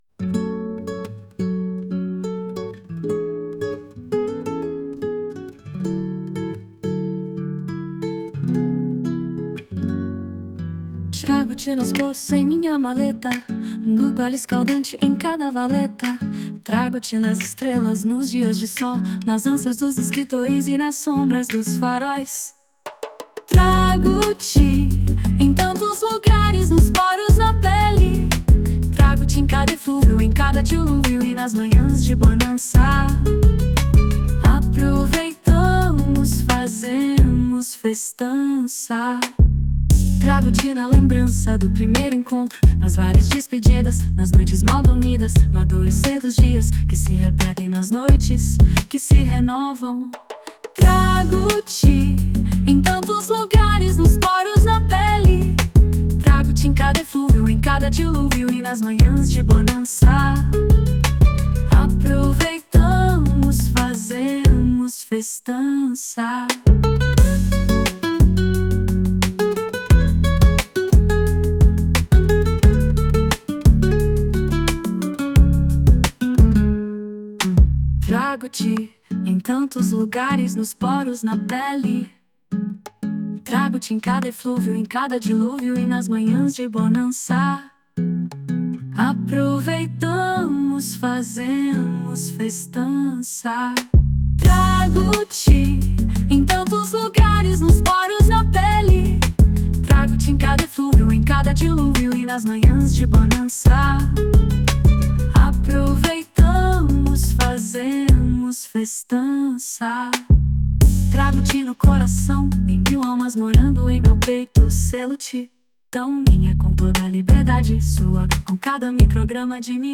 [Vocal Feminino]